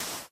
1.21.4 / assets / minecraft / sounds / dig / sand1.ogg
sand1.ogg